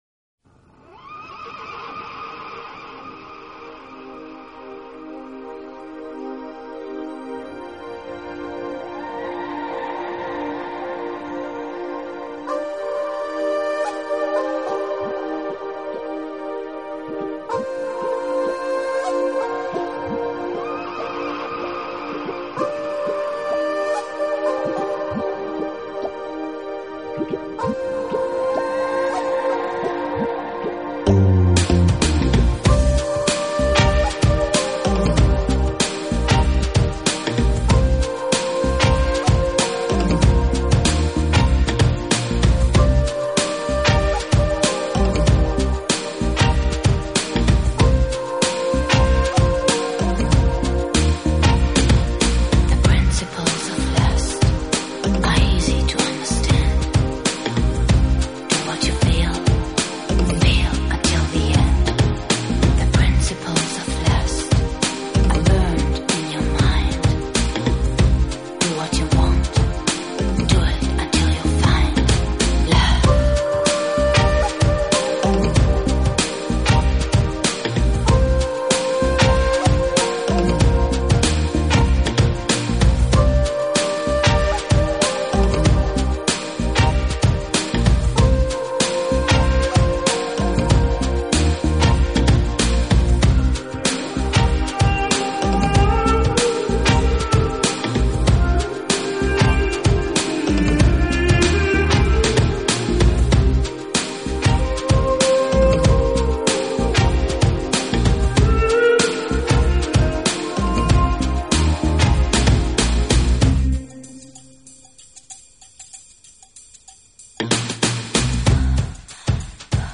Genre........: Chillout